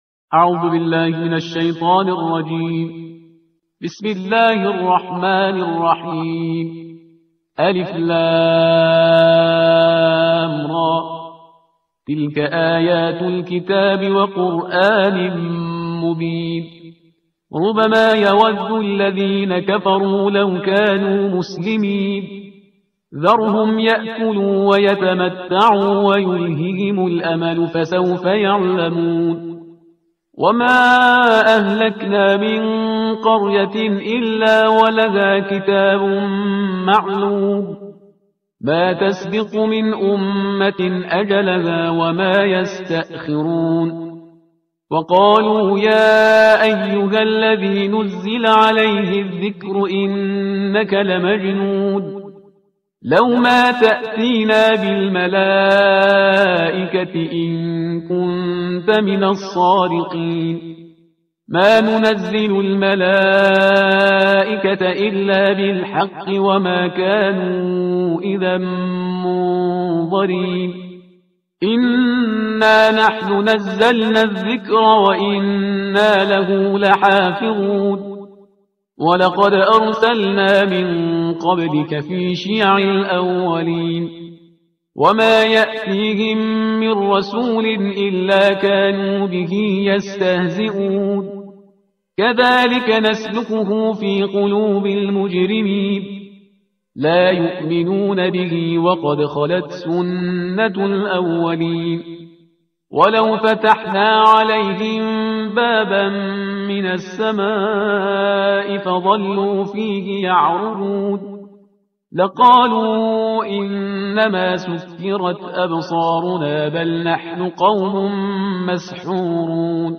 ترتیل صفحه 262 قرآن با صدای شهریار پرهیزگار